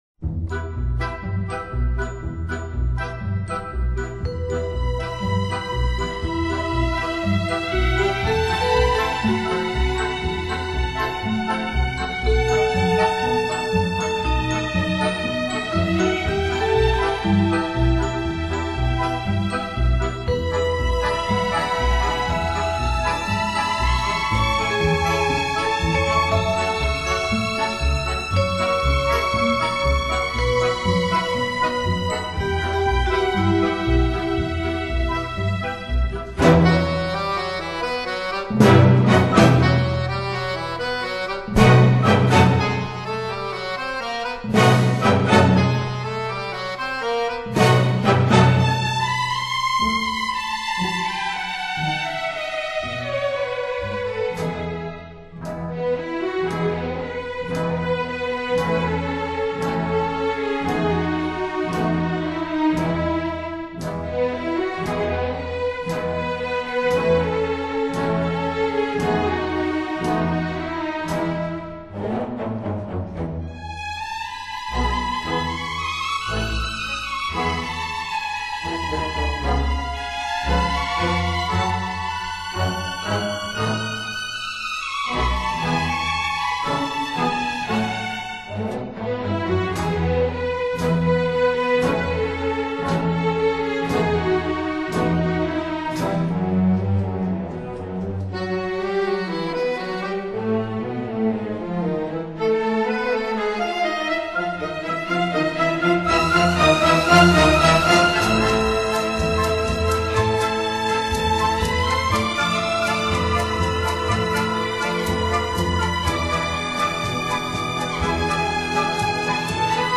在这里，他巧妙地依靠管弦乐队中的弦乐器，找到了一种富有特殊色彩的音响，这种富有特色的弦乐演奏，使他的音乐流传世界各地。